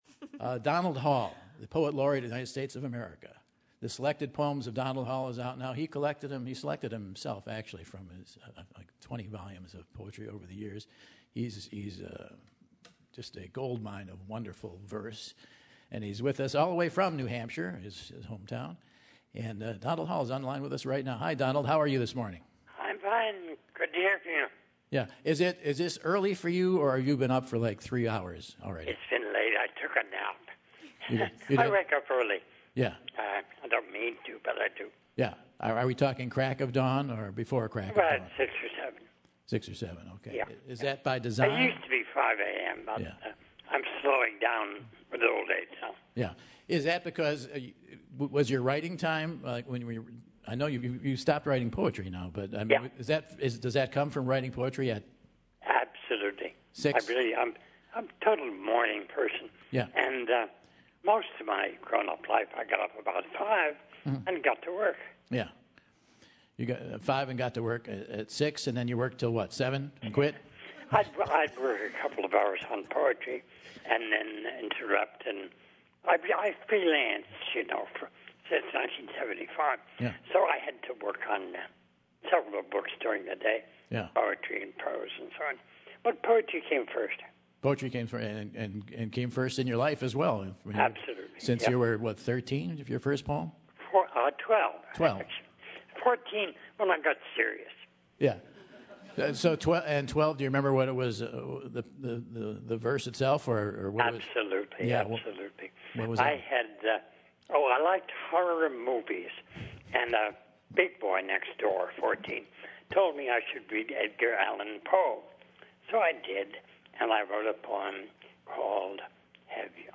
Michael calls up the former US Poet Laureate to chat about poetry, sex, cheerleaders, aging and so much more!